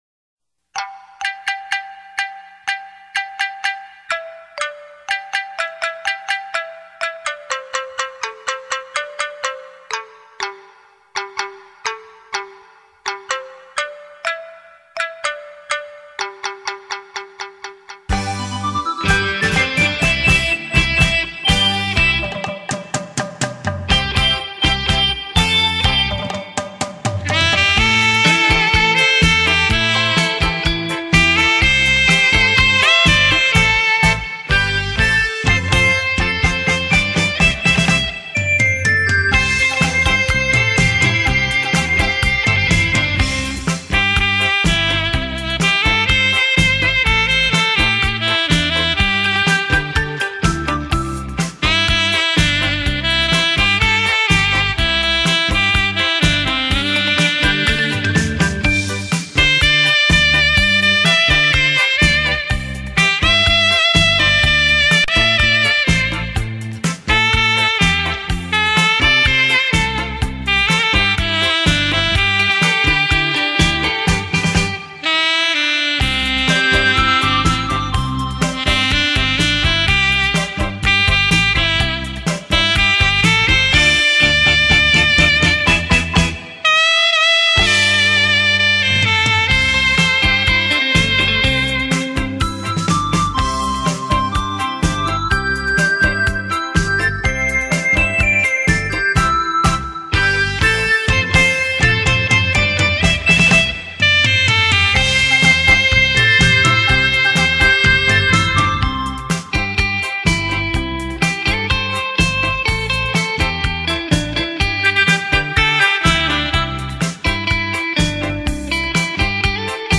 随着萨克斯风乐音的吹奏，
可让聆听的人能很自然的以轻松无压的情绪进入到音乐之中。
这轻柔音乐如水般拂过你的全身，